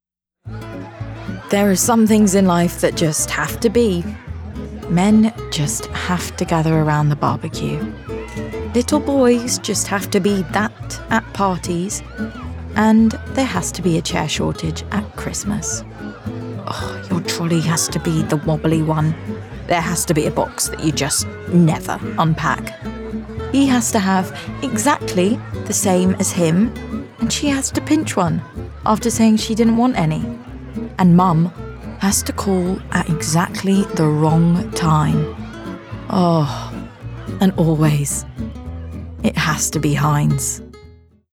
RP ('Received Pronunciation')
Commercial, Bright, Humorous